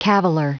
Prononciation du mot caviller en anglais (fichier audio)
Prononciation du mot : caviller